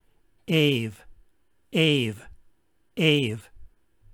In the Koine Greek era the Upsilon shifted to a consonantal \v\ sound in the vowel combinations αυ <